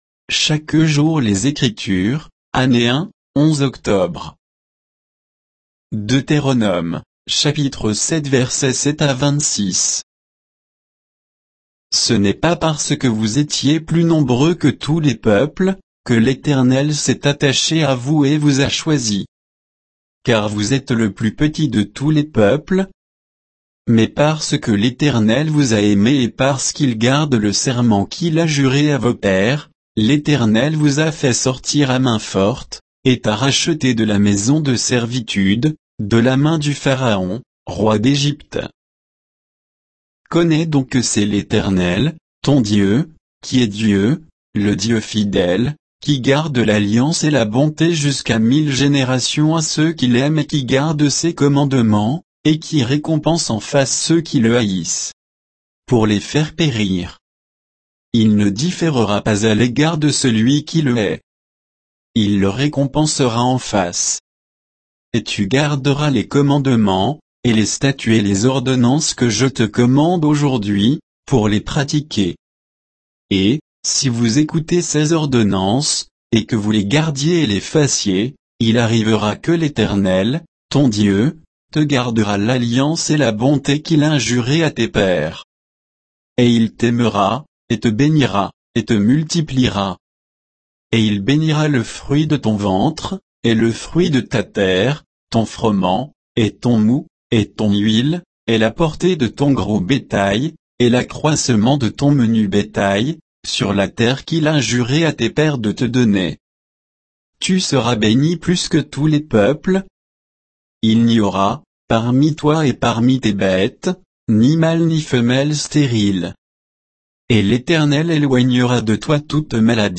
Méditation quoditienne de Chaque jour les Écritures sur Deutéronome 7